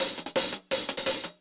Index of /musicradar/ultimate-hihat-samples/170bpm
UHH_AcoustiHatB_170-01.wav